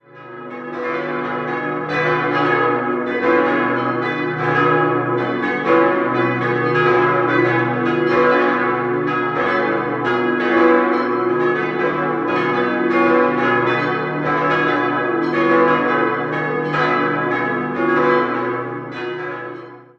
6-stimmiges Geläut: h°-cis'-dis'-fis'-gis'-c''' Die Glocken 1, 3, 4 und 5 wurden im Jahr 1946 von Karl Czudnochowsky in Erding gegossen.